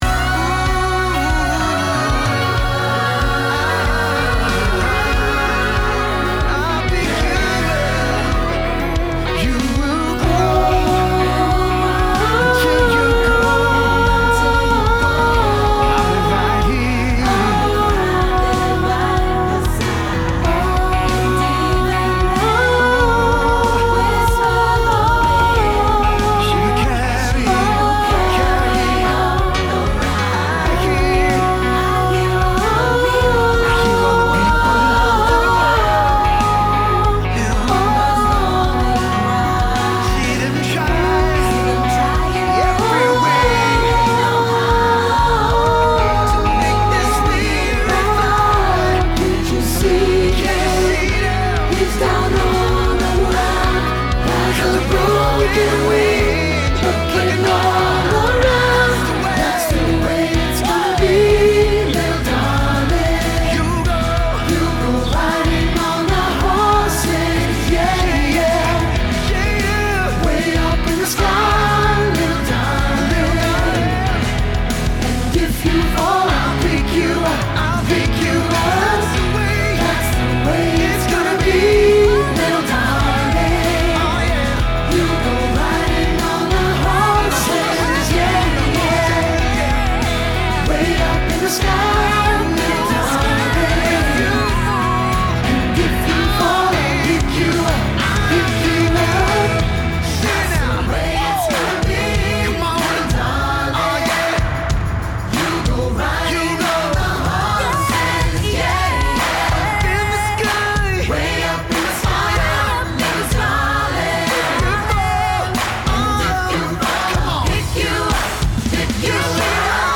The Horses – AGC – Low Groups Panned
The-Horses-AGC-Low-Groups-panned.mp3